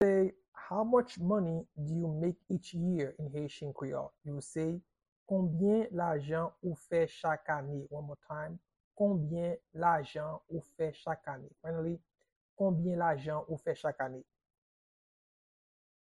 Pronunciation and Transcript:
How-to-say-How-much-money-do-you-make-each-year-in-Haitian-Creole-–Konbyen-lajan-ou-fe-chak-ane-pronunciation.mp3